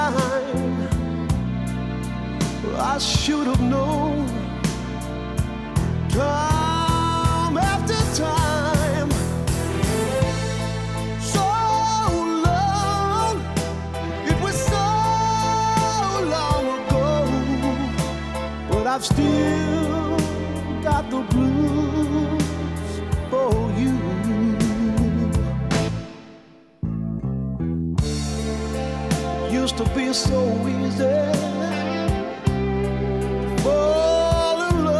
# Contemporary Blues